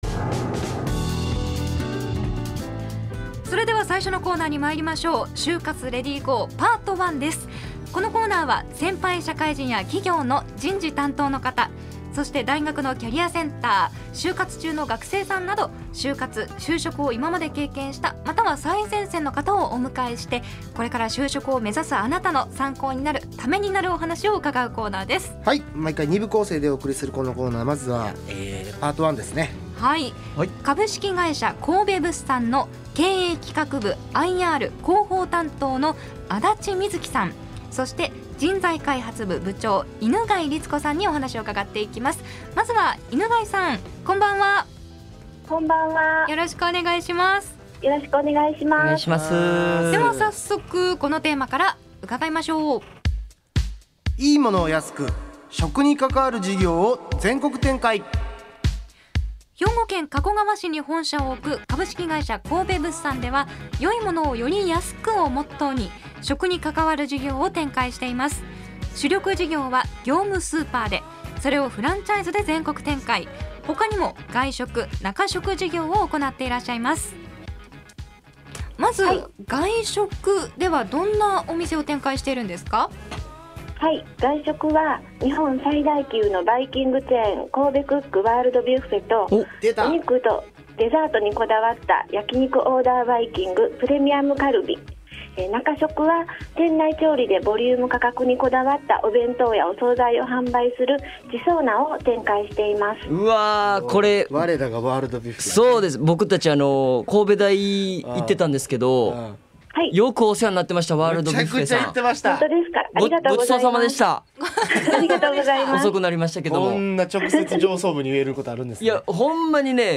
【放送音声】『ネイビーズアフロのレディGO！HYOGO』2021年9月17日放送回より